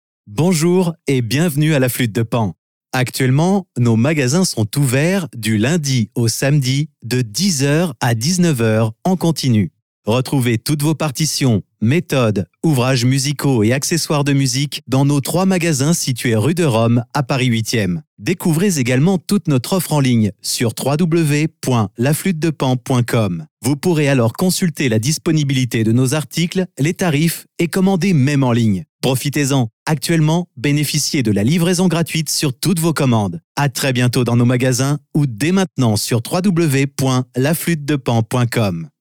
Naturelle, Enjouée, Polyvalente, Mature, Amicale
Téléphonie